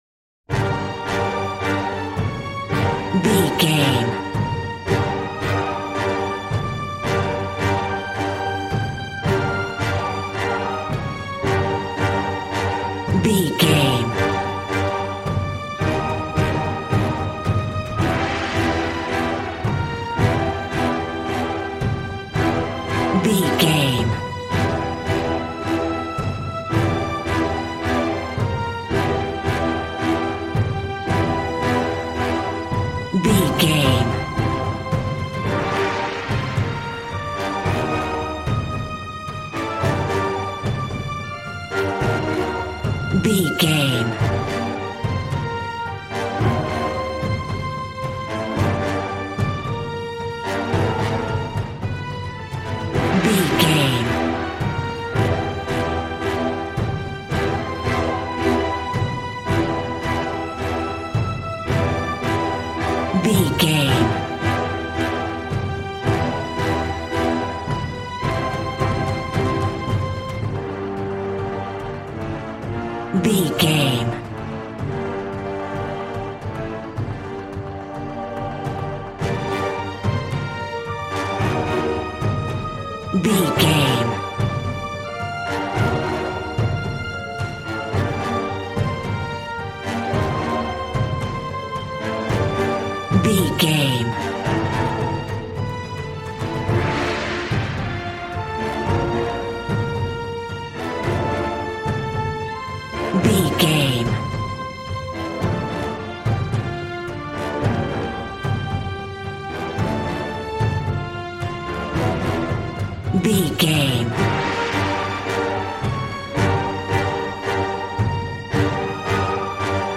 Regal and romantic, a classy piece of classical music.
Ionian/Major
regal
cello
double bass